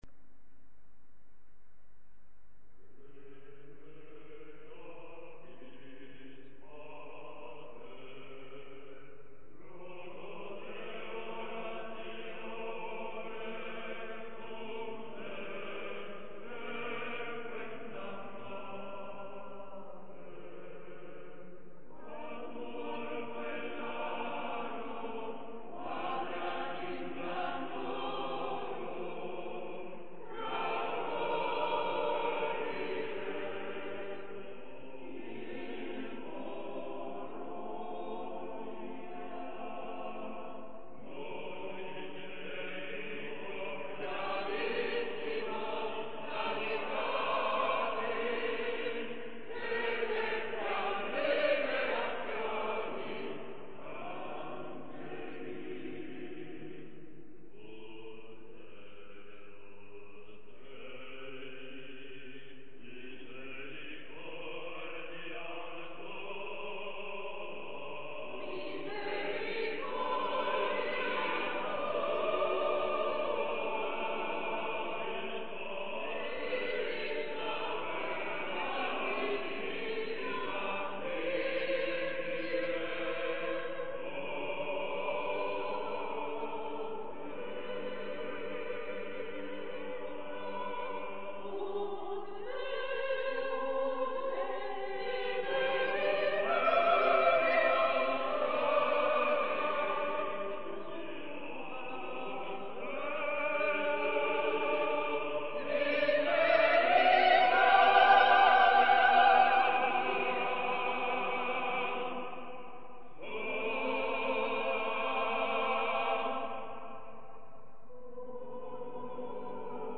Coro "Rose di Gerico"